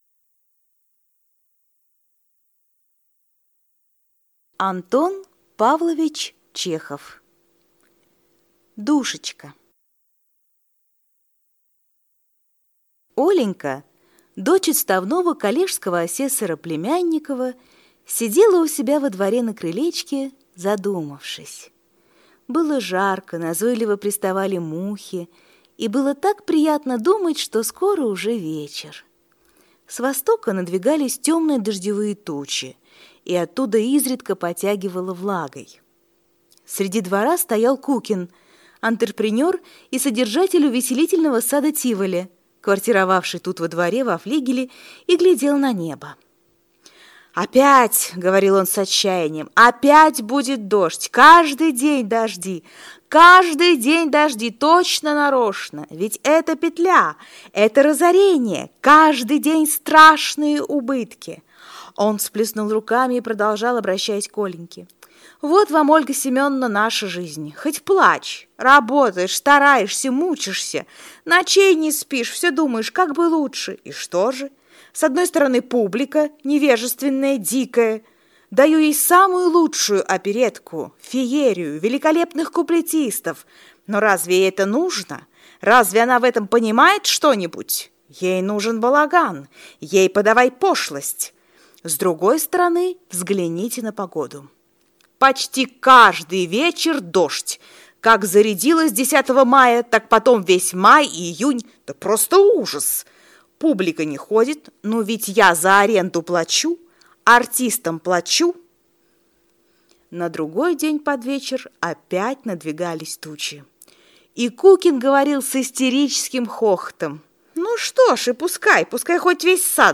Аудиокнига Душечка | Библиотека аудиокниг